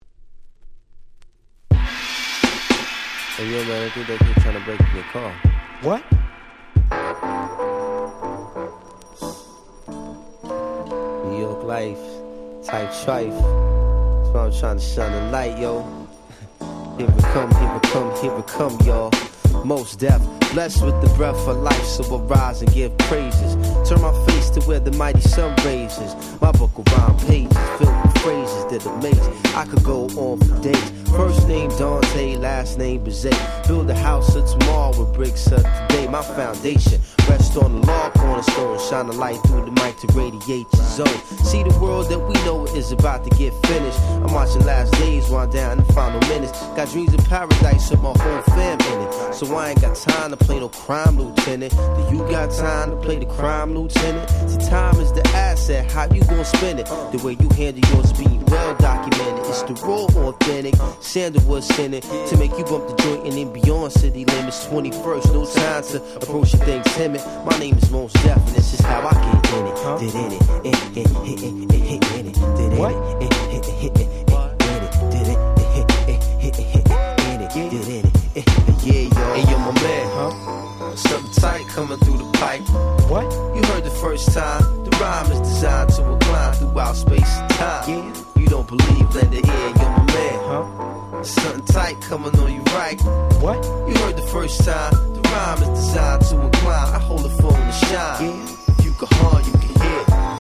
97' Smash Hit Hip Hop !!